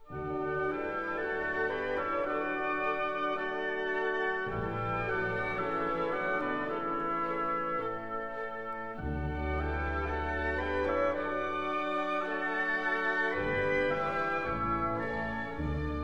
The theme is in D major.